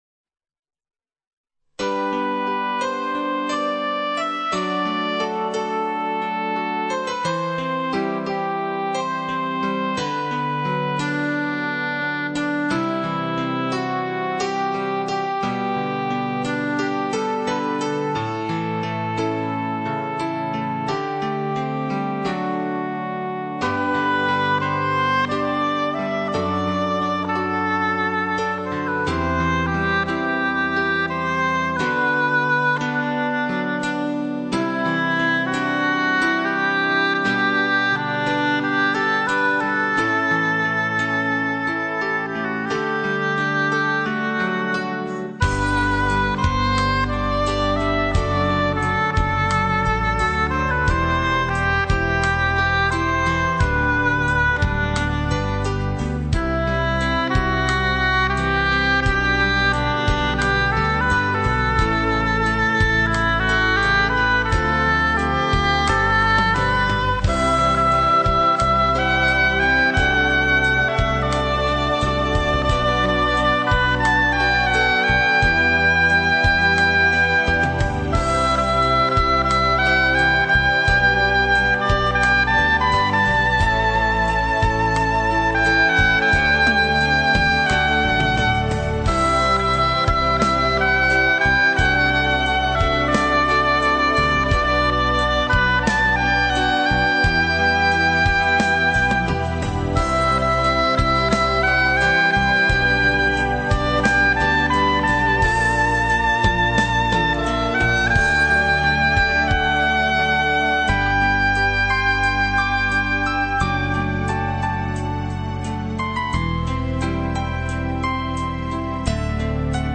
餐厅背景音乐，祝大家在悠扬的音乐声中胃口大开
吉它